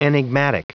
Prononciation du mot : enigmatic
enigmatic.wav